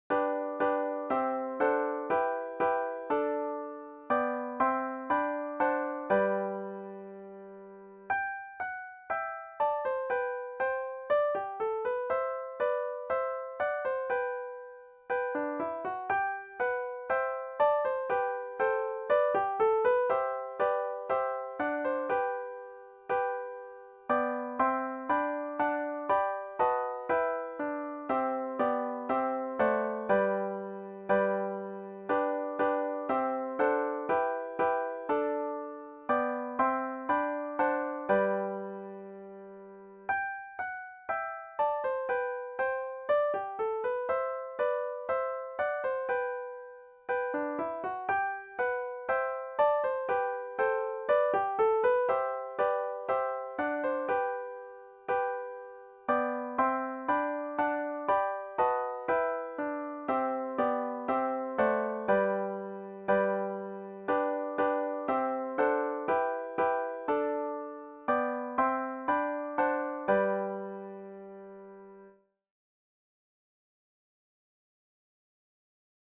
four guitar